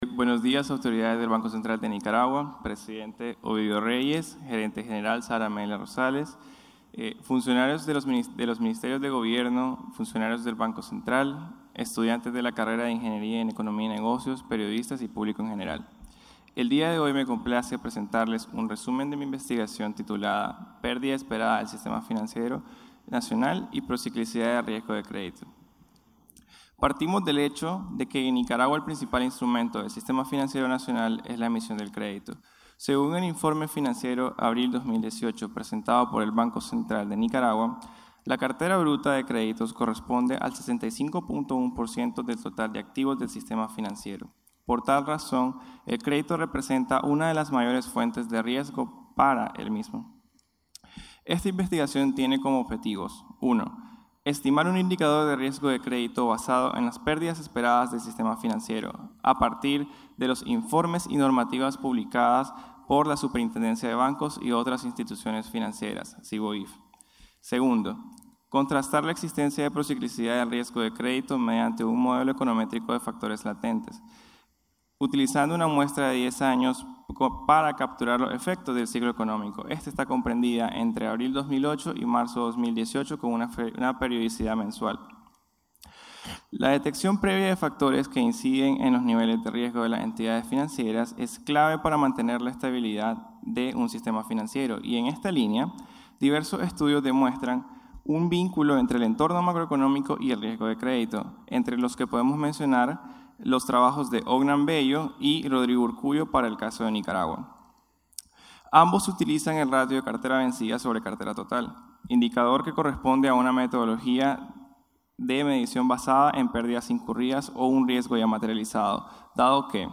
Audio ganador primer lugar